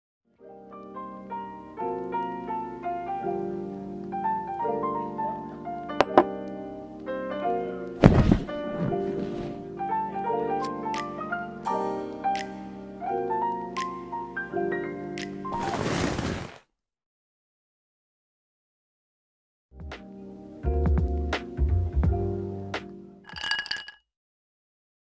誘ってるよね 【シチュボ 1人声劇】